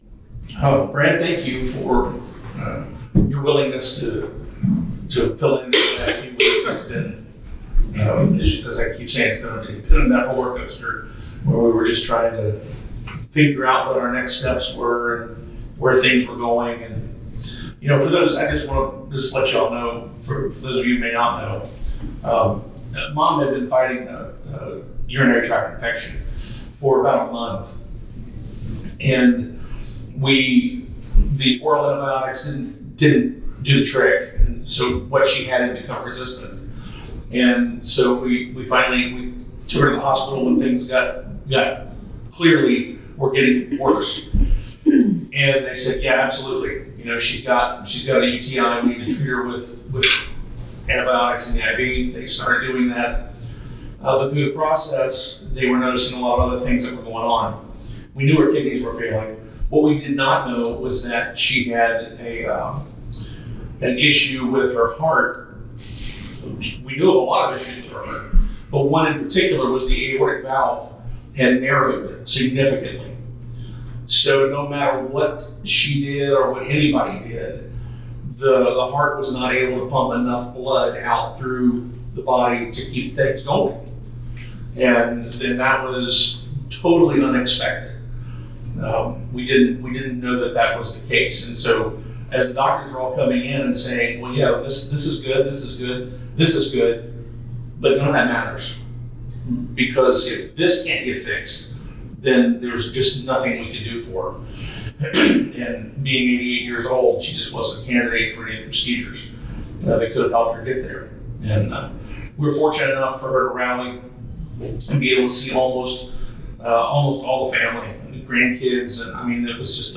Faith and Friends Sunday School Class | Belleview Baptist Church